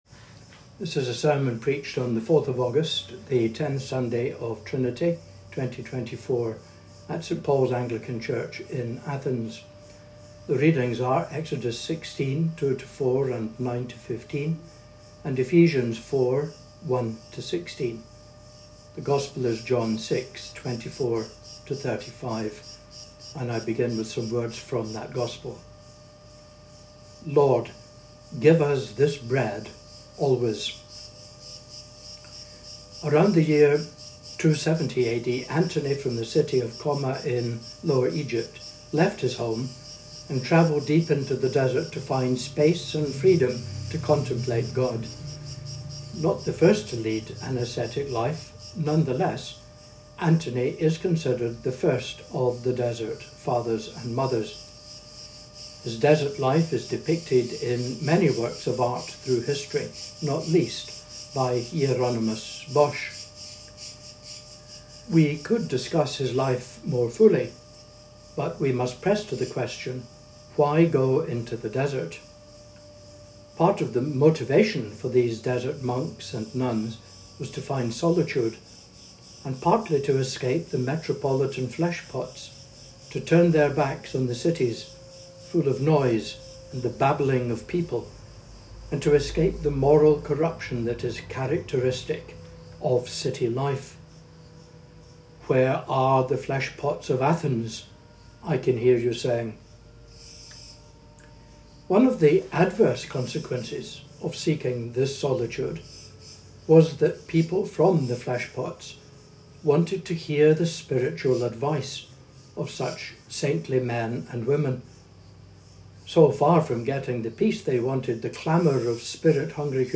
Aug-4th-2024-Sermon-Audio.m4a